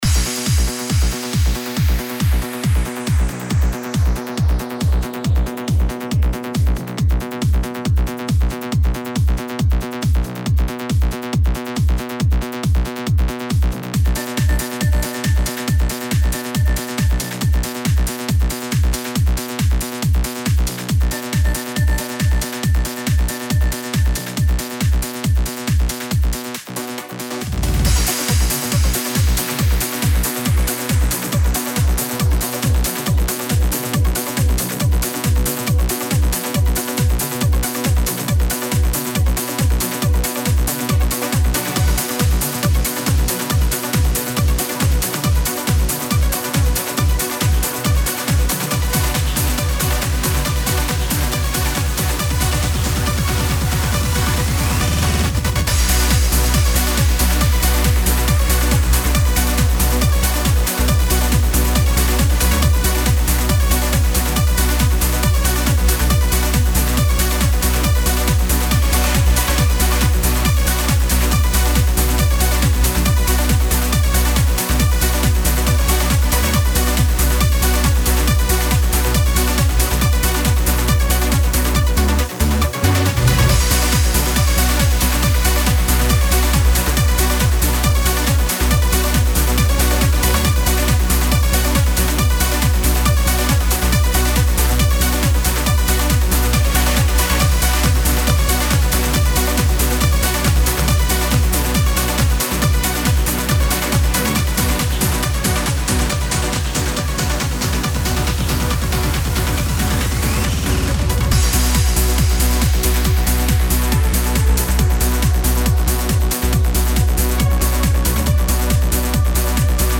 Genre: Progressive.